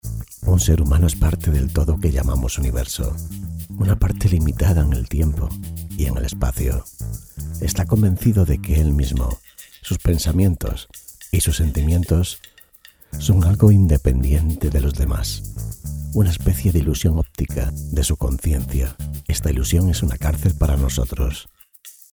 locutor, spanish voice over